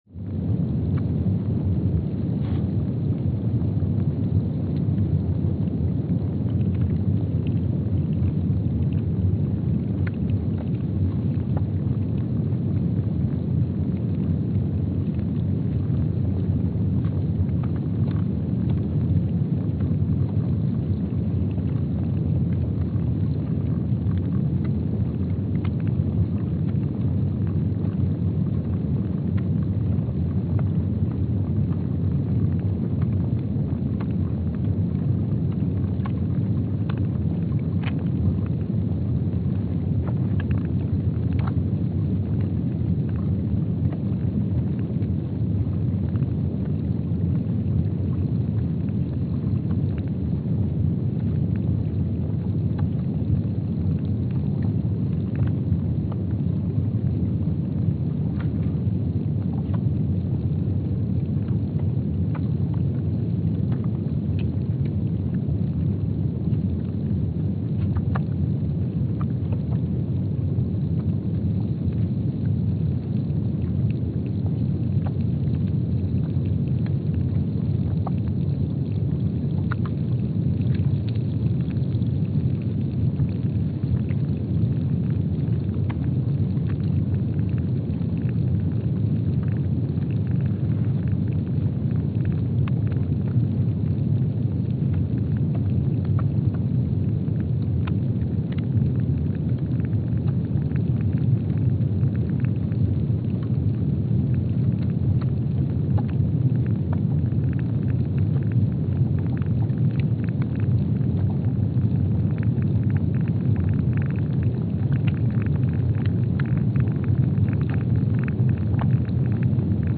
Palmer Station, Antarctica (seismic) archived on April 14, 2025
Station : PMSA (network: IRIS/USGS) at Palmer Station, Antarctica
Speedup : ×500 (transposed up about 9 octaves)
Loop duration (audio) : 05:45 (stereo)
Gain correction : 25dB